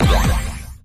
Appear_Scatter_Sound_elimin.mp3